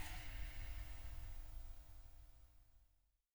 floppy_525_motor_end.wav